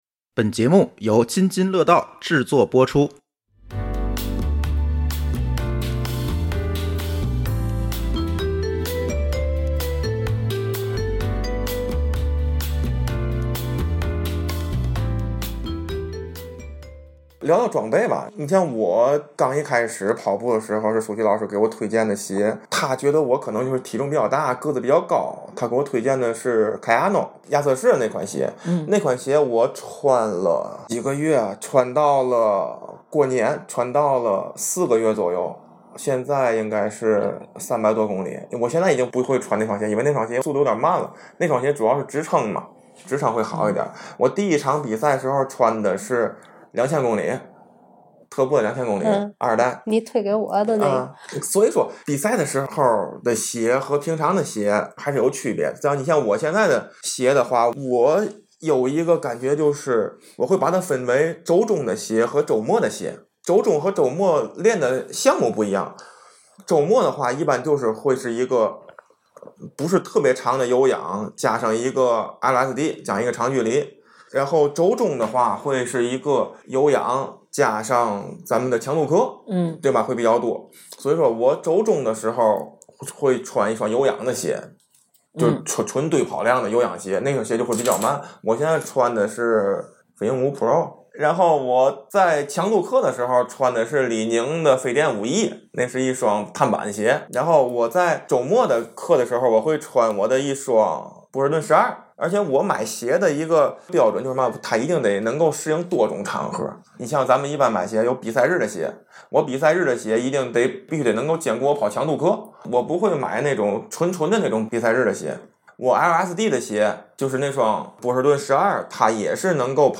语音留言